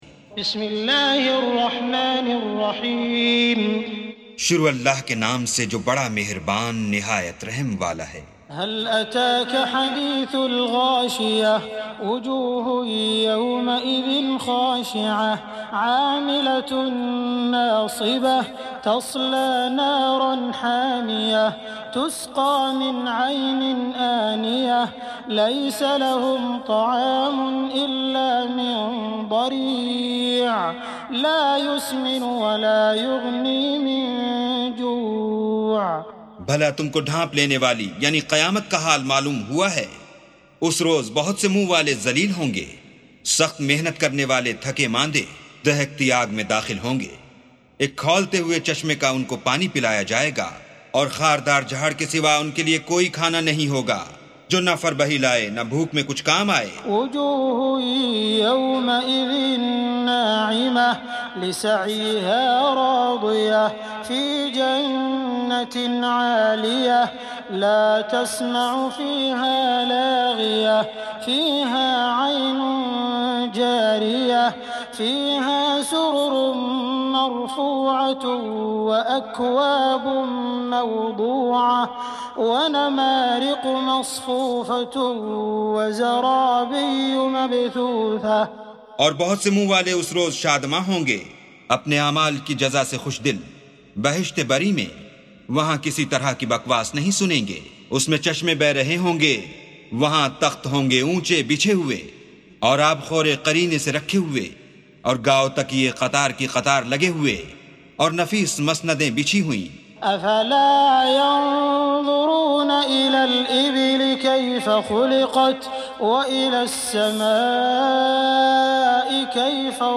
سُورَةُ الغَاشِيَةِ بصوت الشيخ السديس والشريم مترجم إلى الاردو